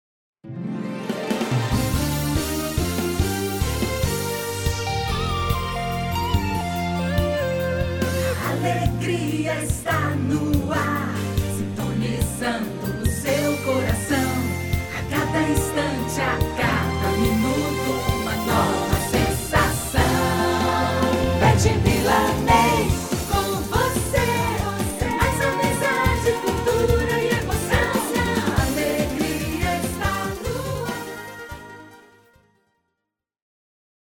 Spots e vinhetas
Seja com locução feminina, masculina, interpretativa ou caricata, o spot vai desde o rádio ao podcast levando, de forma clara e objetiva, sua mensagem para o seu público-alvo.